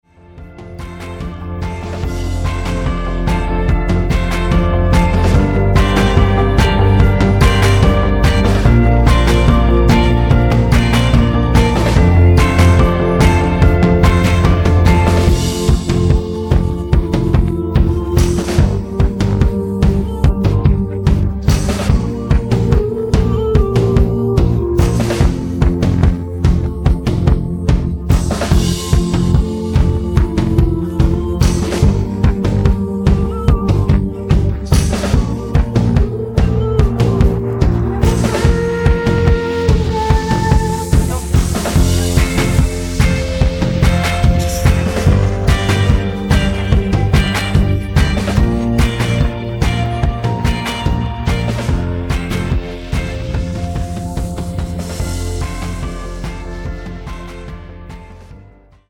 음정 원키 3:51
장르 가요 구분 Voice MR